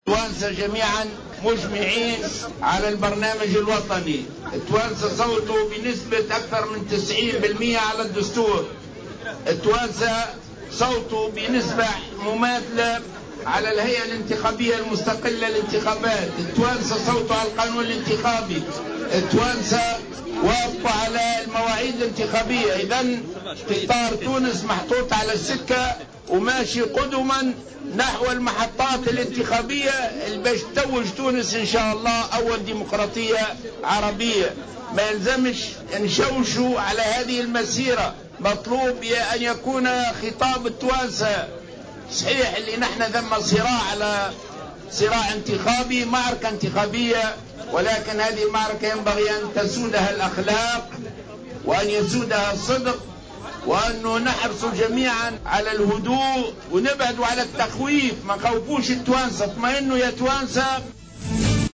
دعا رئيس حركة النهضة راشد الغنوشي اليوم الخميس خلال ندوة للتعريف بالمترشحين في القائمات الانتخابية التشريعية إلى عدم التشويش على الانتخابات.